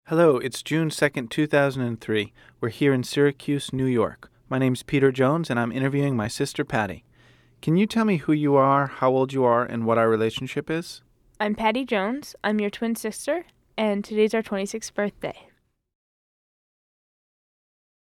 Good-Audio.mp3